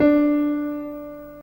G_SOL.ogg